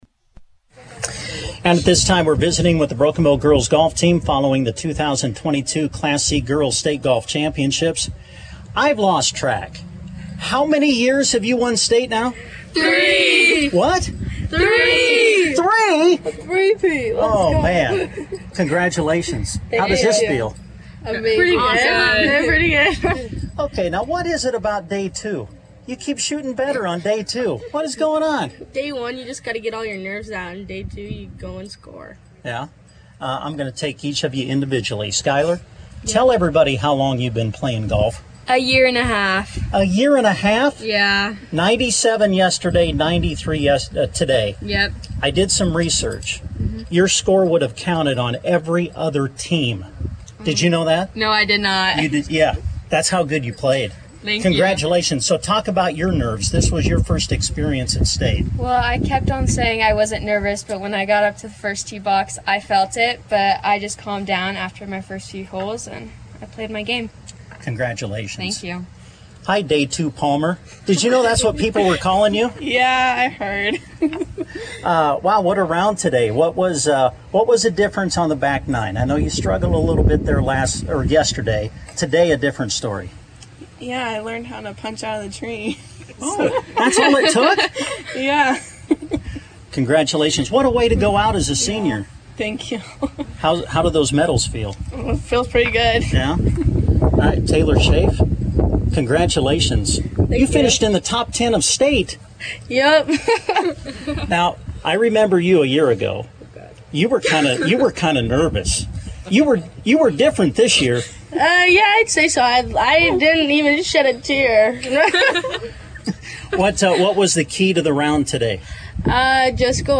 Check out our interview with the team following the final round of the tournament on Tuesday.